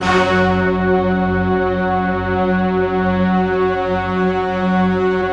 Index of /90_sSampleCDs/Optical Media International - Sonic Images Library/SI1_StaccatoOrch/SI1_Sfz Orchest